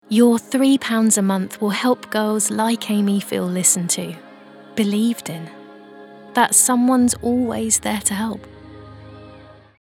Fresh British female voice with a naturally engaging and warm tone. Often booked for conversational and warm reads and Neutral English/global accents.